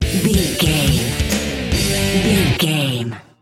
Ionian/Major
drums
electric guitar
bass guitar
Sports Rock
hard rock
lead guitar
aggressive
energetic
intense
powerful
nu metal
alternative metal